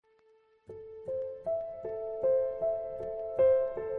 Cat Meow
Cat Meow is a free animals sound effect available for download in MP3 format.
037_cat_meow.mp3